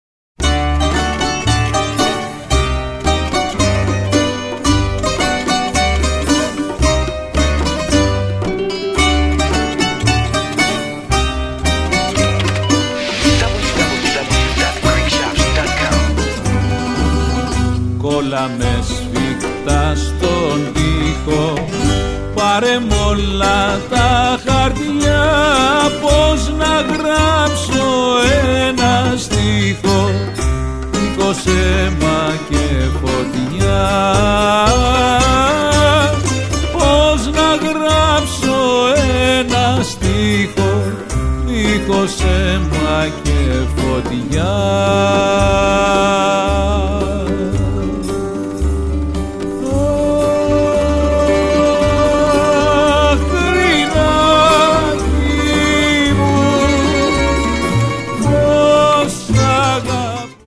forcefulness melodies and rhythms